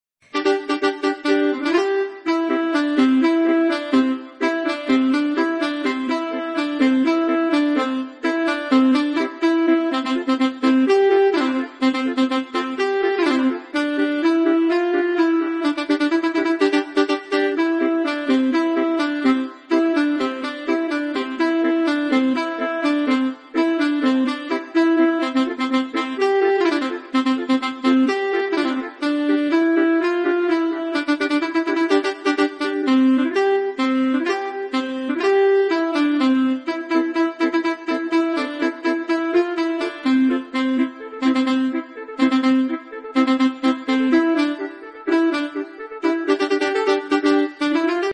Bus kids panda, basuri manual sound effects free download
Bus kids panda, basuri manual pianika 😁